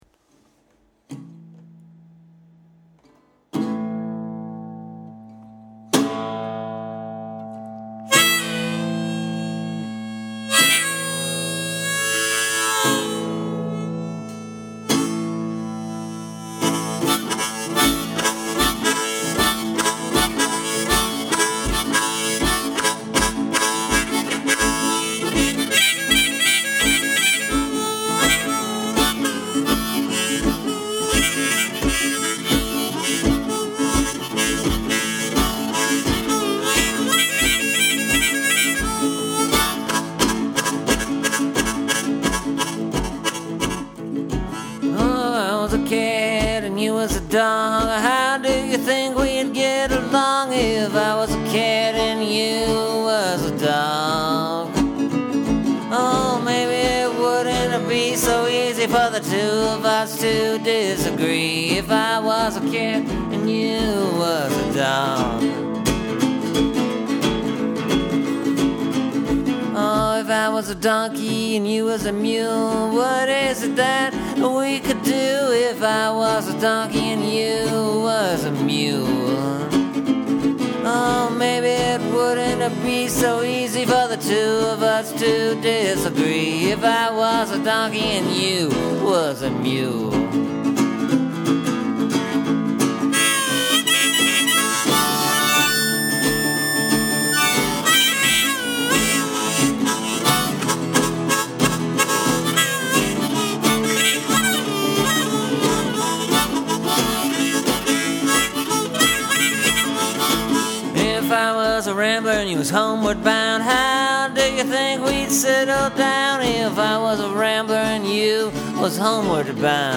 It’s a pretty typical blues tune.